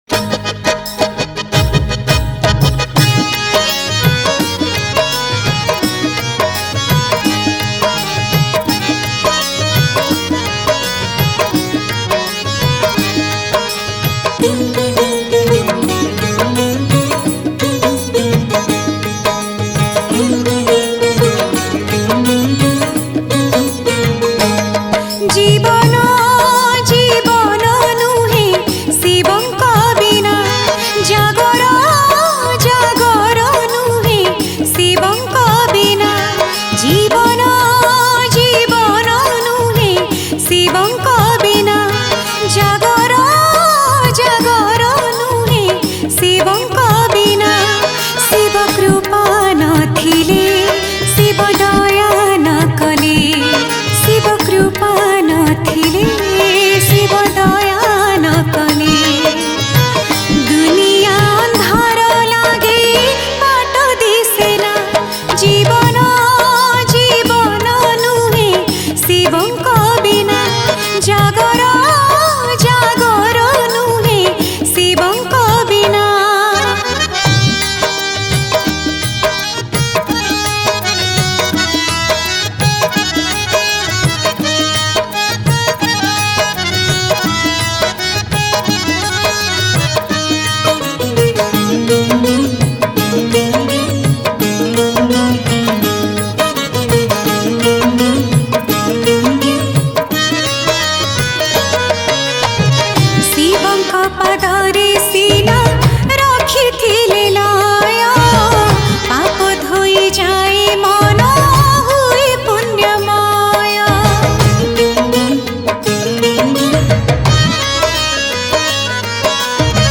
Category: New Odia Bhakti Songs 2022